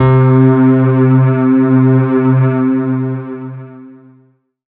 37k01pad1-c.wav